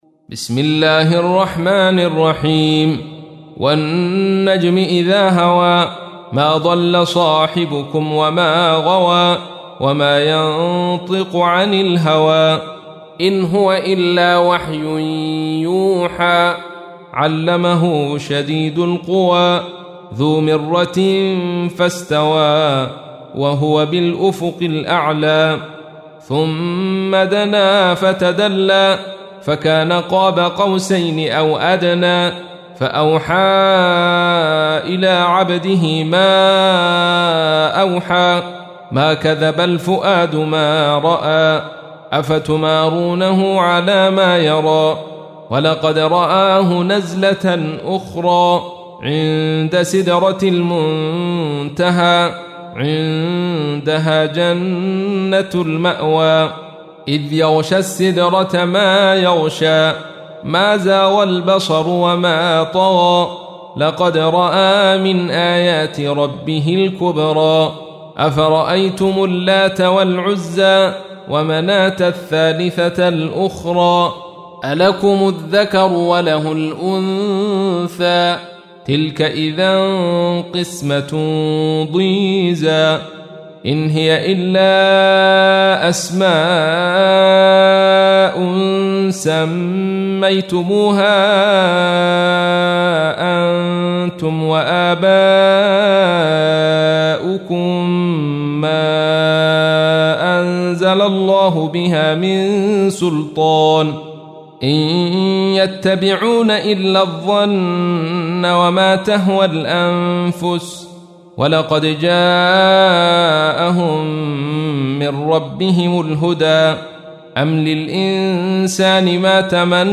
سورة النجم | القارئ عبدالرشيد صوفي